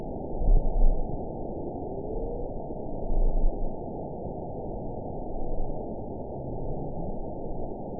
event 922394 date 12/30/24 time 08:35:45 GMT (11 months ago) score 8.99 location TSS-AB10 detected by nrw target species NRW annotations +NRW Spectrogram: Frequency (kHz) vs. Time (s) audio not available .wav